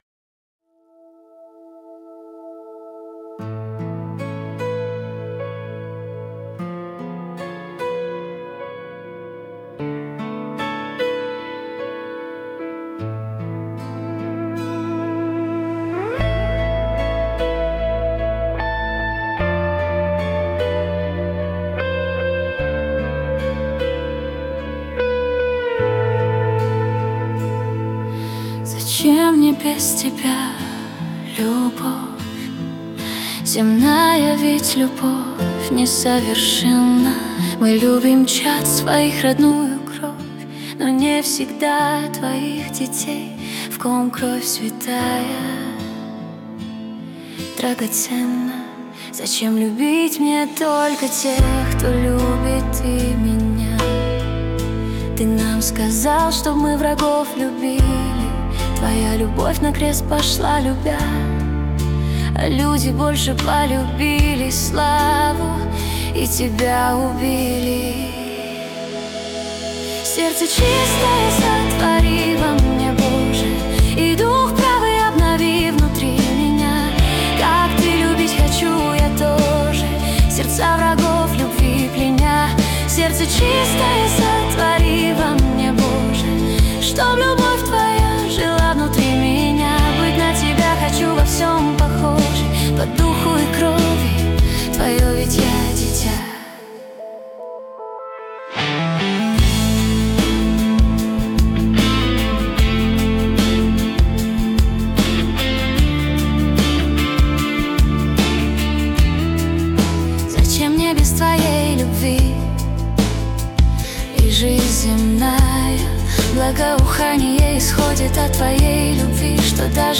песня ai
176 просмотров 472 прослушивания 67 скачиваний BPM: 76